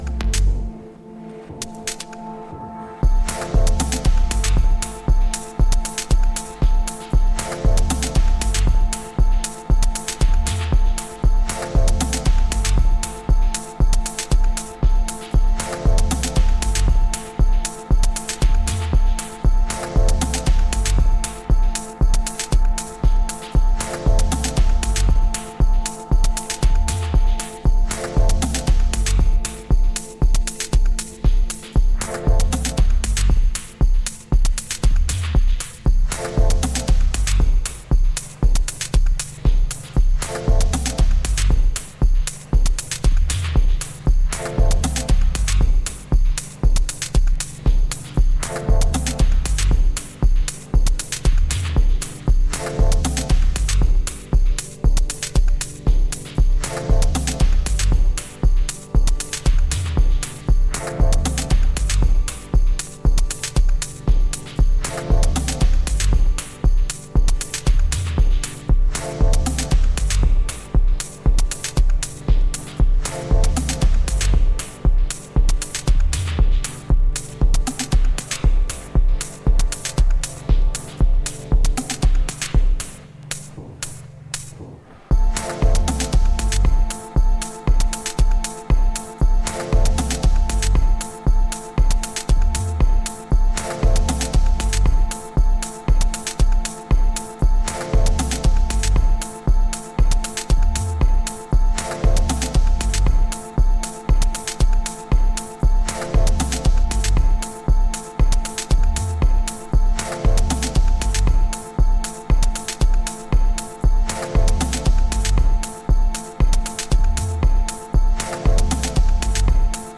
Excellent dreamy and very well-produced foggy electronics!
Electronix Techno